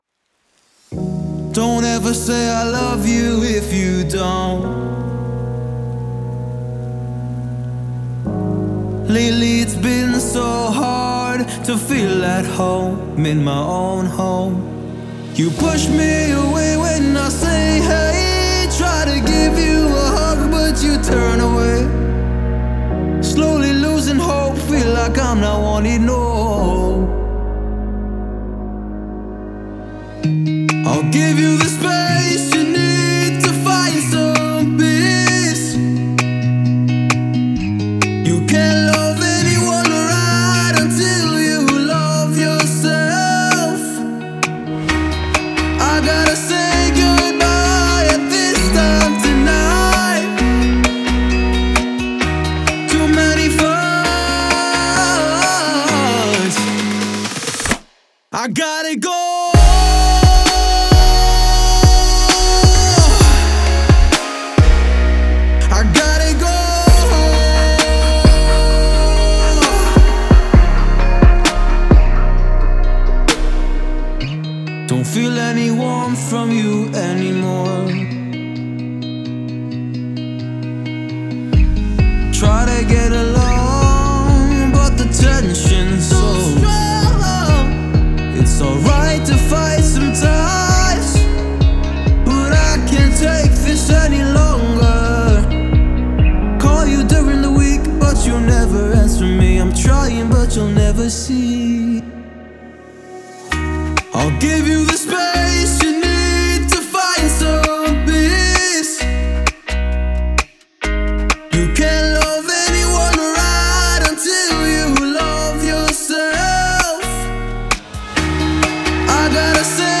это динамичная песня в жанре поп